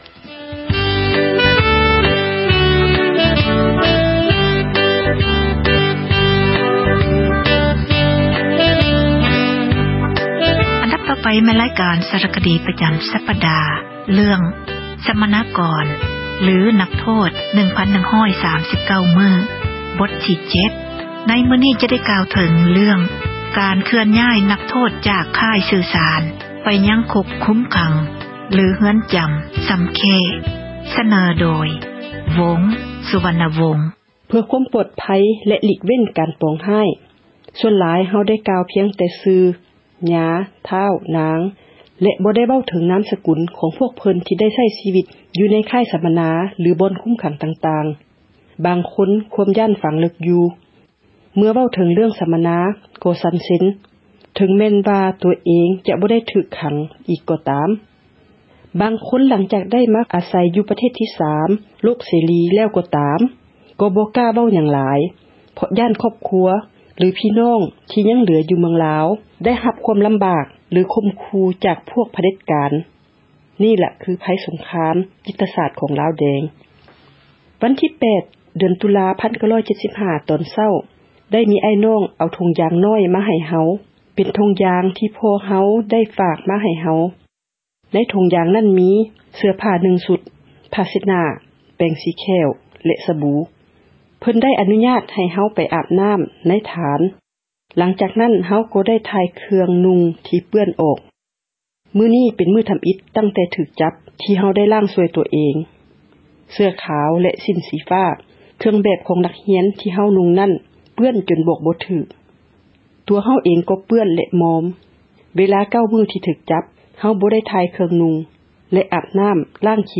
ສາຣະຄະດີ ເຣື້ອງ ສັມມະນາກອນ ຫຼື ນັກໂທດ 1,139 ມື້ ບົດທີ 7 ມື້ນີ້ ຈະໄດ້ກ່າວເຖິງ ການຍ້າຍ ນັກໂທດ ຈາກ ຄ້າຍສື່ສານ ໄປ ຄຸກຄຸມຂັງ “ຊໍາເຄ້”